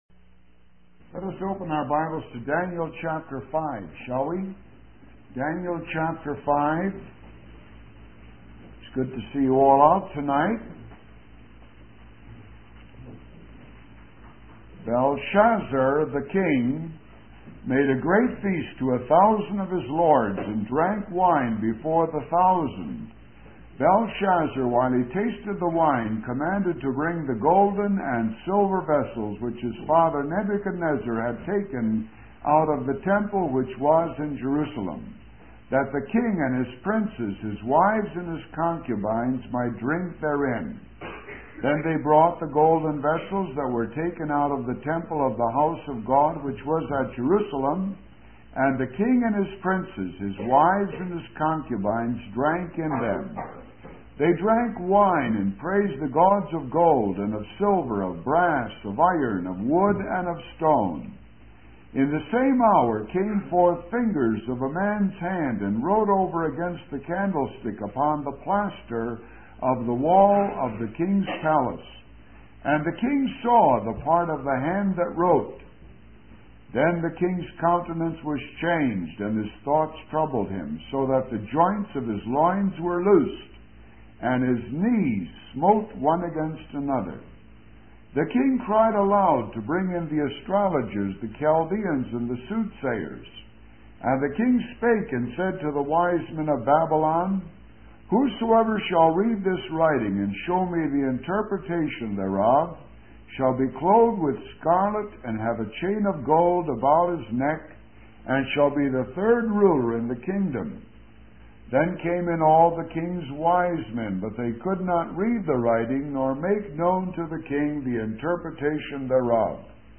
In this sermon, the preacher discusses a biblical story about a king who was terrified by the appearance of a man's hand writing on a wall.